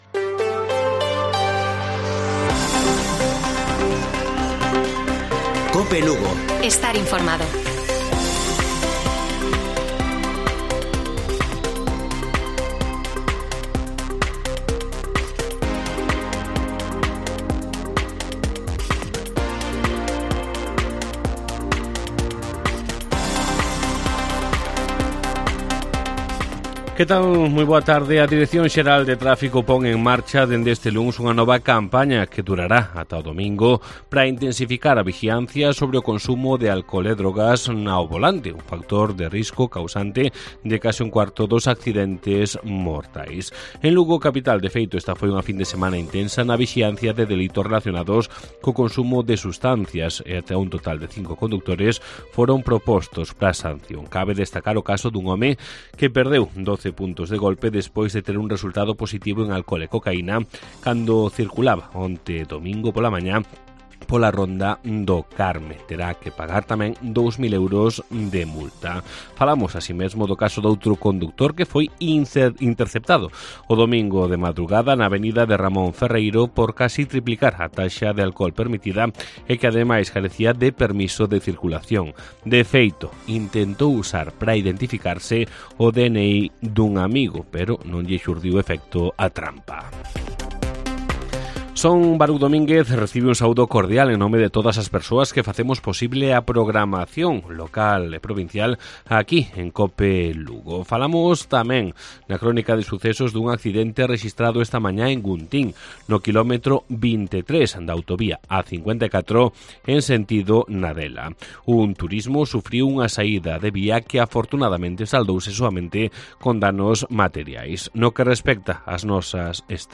Informativo Mediodía de Cope Lugo. 8 DE AGOSTO. 14:20 horas